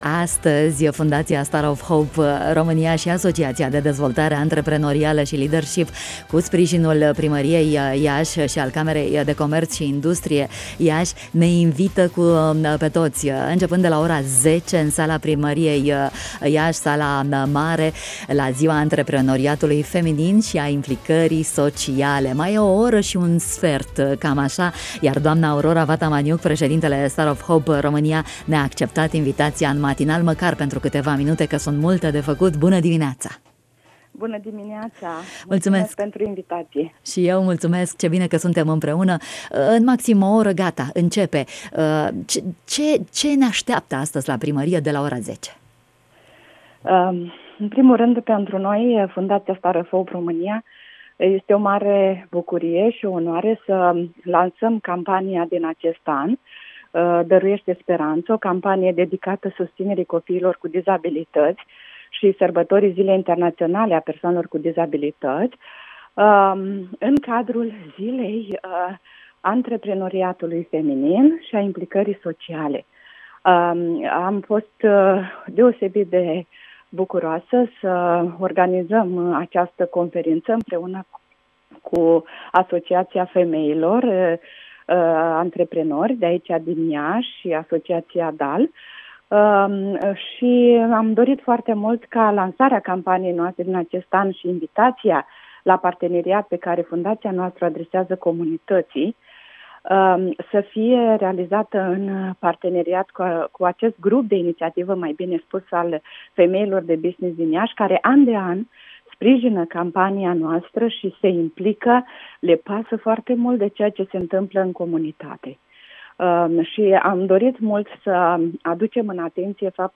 În direct